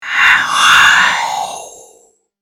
Scary Ghost Whisperer Sound Button - Free Download & Play